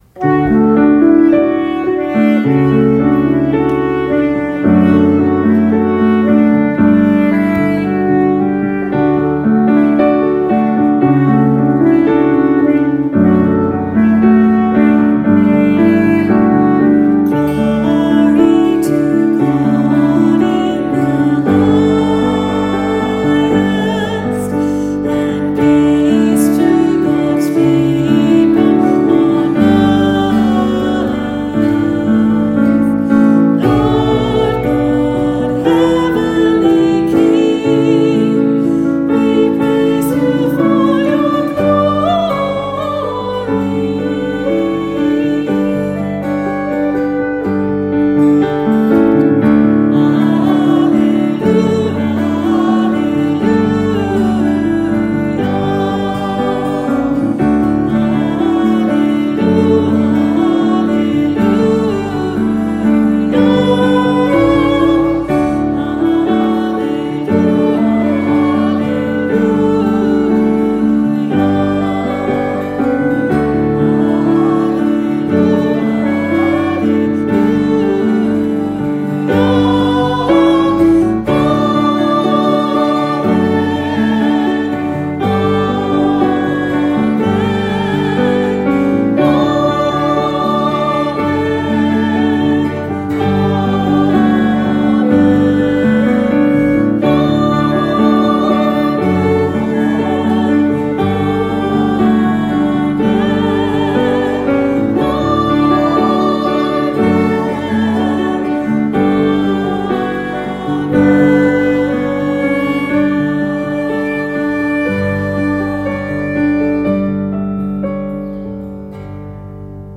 for unison voices (optional descant) and piano/guitar
Listen to a live recording, view the score.
Unison (optional descant) with piano